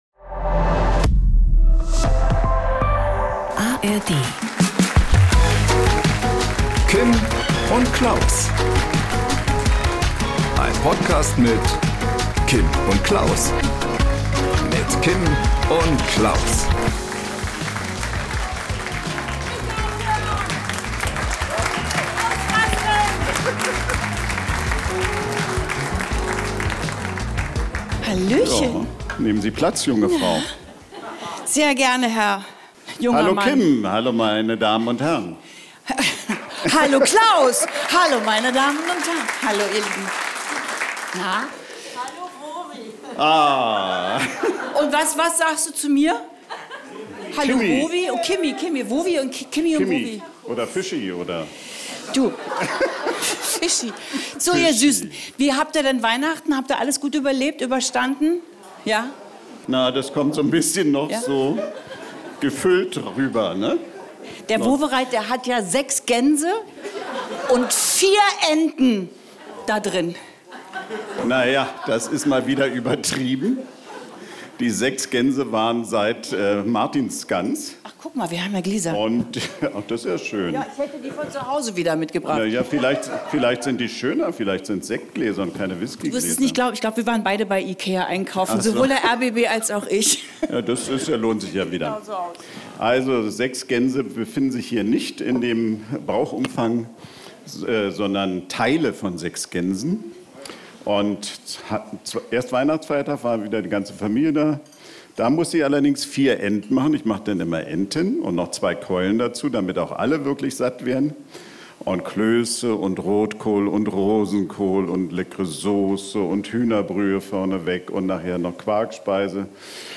Live mit Publikum in der Dachlounge des rbb.